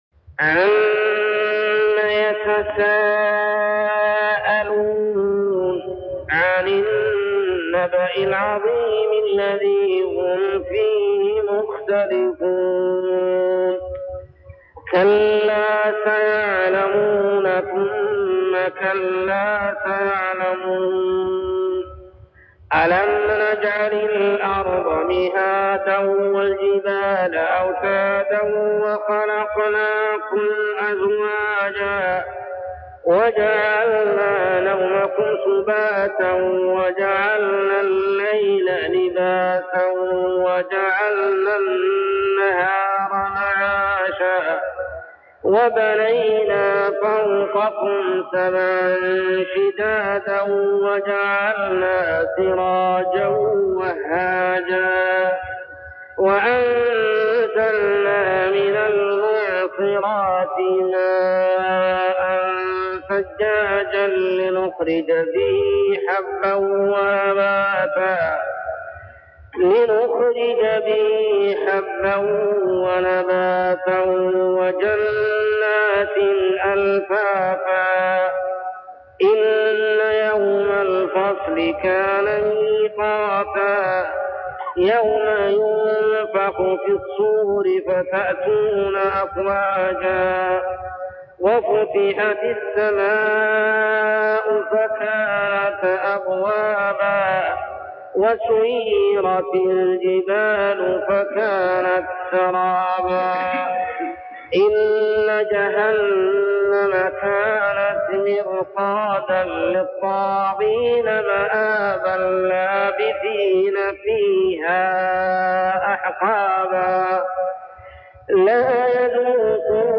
تلاوة من صلاة الفجر لسورة النبإ كاملة عام 1399هـ | Fajr prayer Surah Al-Naba > 1399 🕋 > الفروض - تلاوات الحرمين